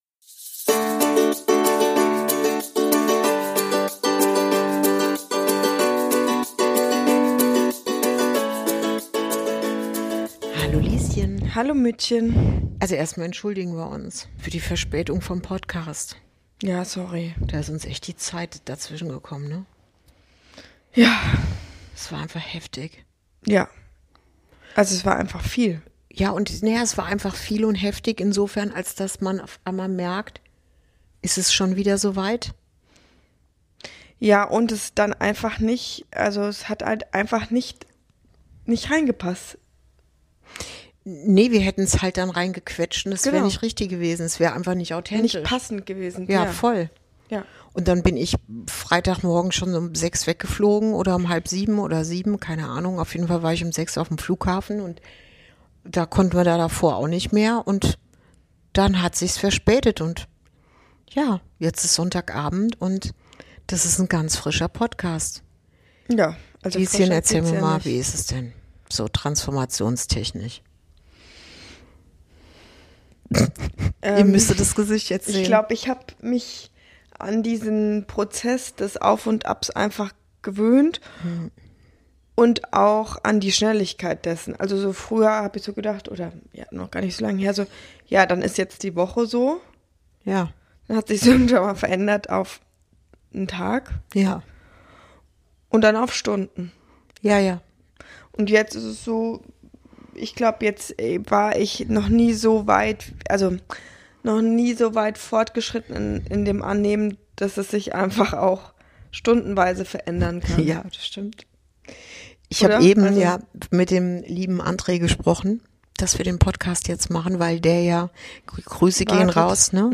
061: Warum dein Schlaf sich gerade verändert ~ Inside Out - Ein Gespräch zwischen Mutter und Tochter Podcast